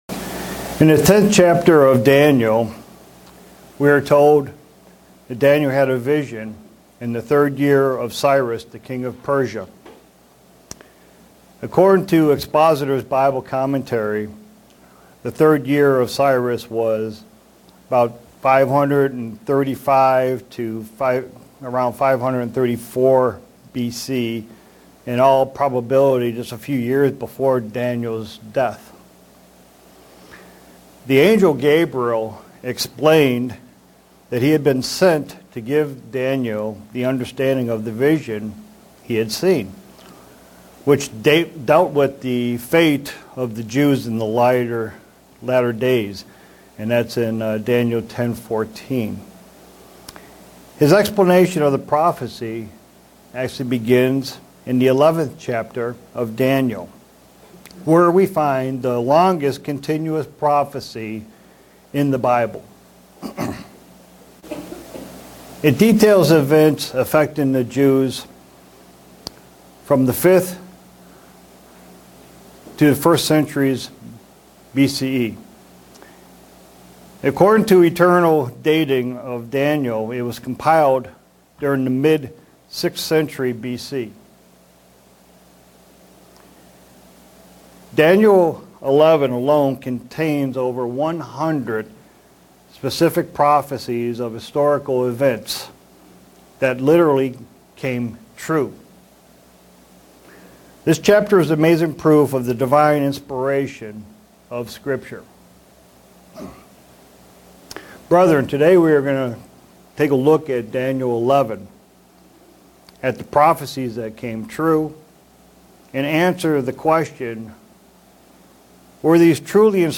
Given in Buffalo, NY
Print Where the prophecies of Daniel inspired by God or fabricated and written down centuries after Daniel? sermon Studying the bible?